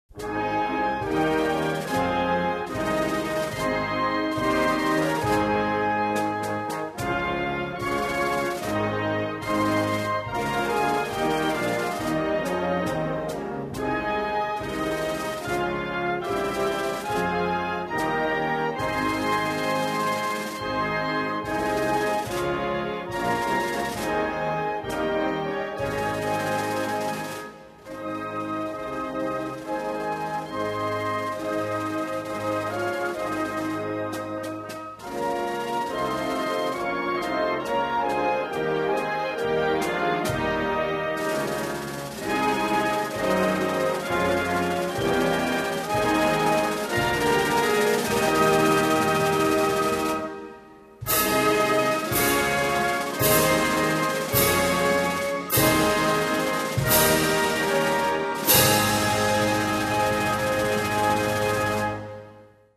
New_Zealand_national_anthem_performed.MP3